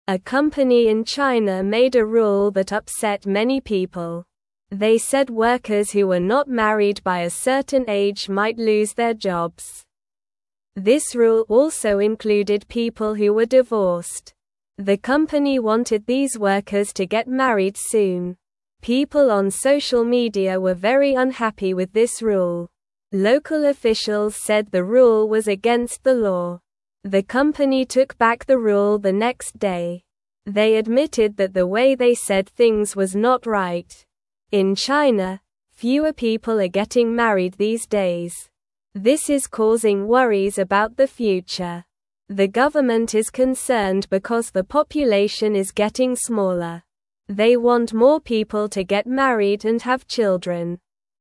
Slow
English-Newsroom-Lower-Intermediate-SLOW-Reading-Company-Makes-Workers-Marry-or-Lose-Their-Jobs.mp3